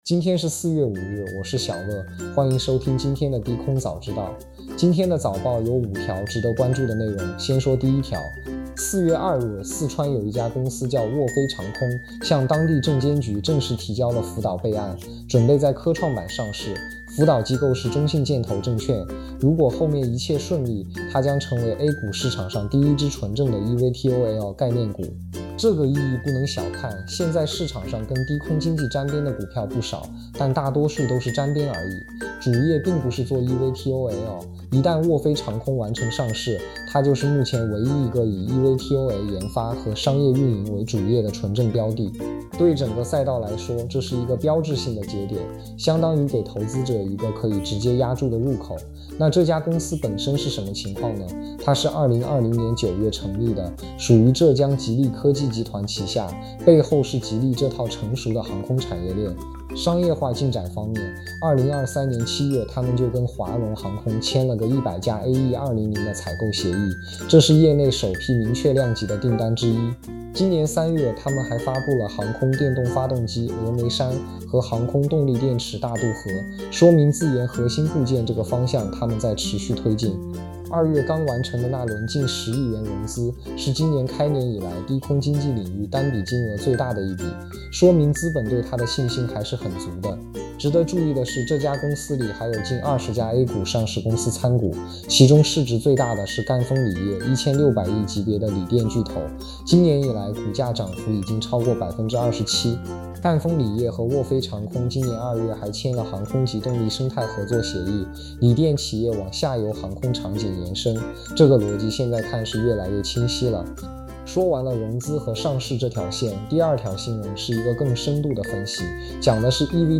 每日低空经济行业资讯播客，聊无人机、eVTOL、低空基础设施和政策动态，用朋友聊天的方式说行业的事儿。